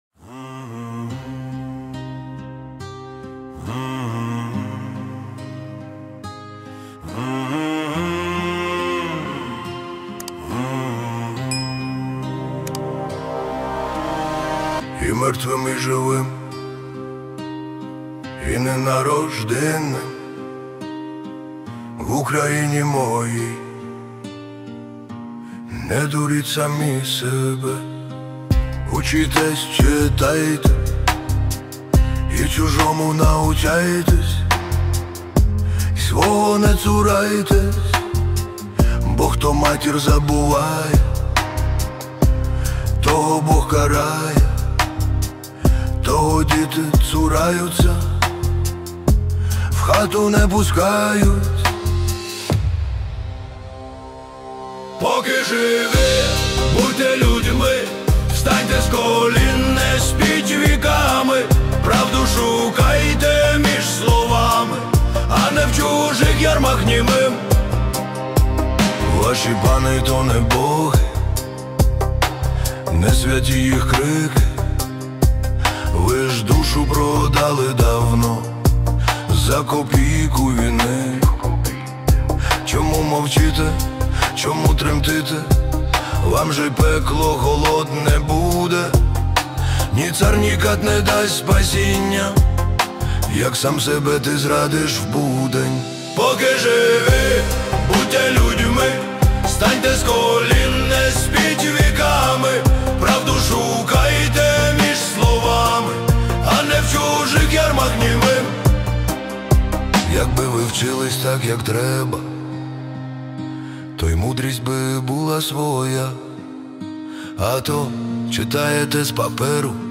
Deep House Pop Remix 2025